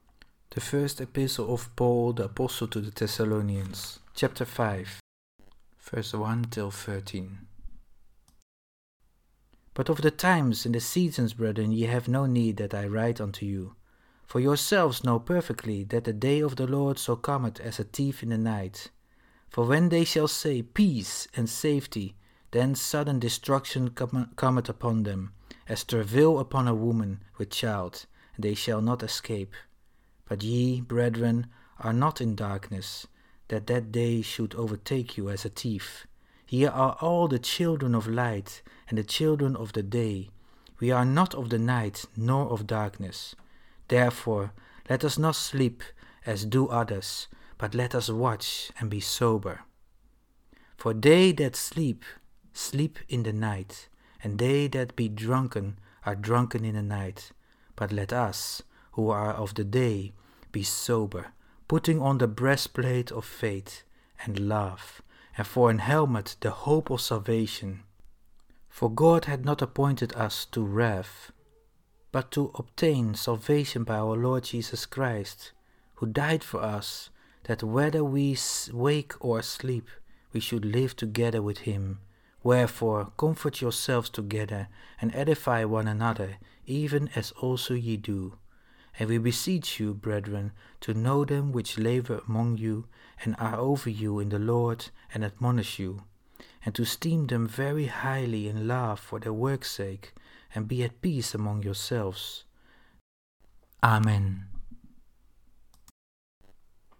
topic: Bible reading
Bible reading. (KJV)